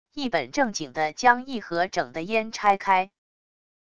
一本正经地将一盒整的烟拆开wav音频